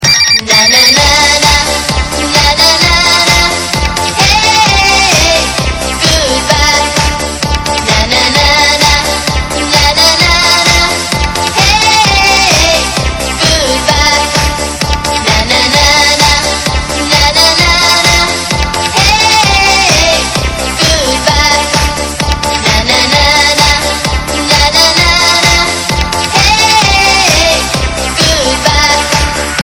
DJ舞曲下载
分类: DJ铃声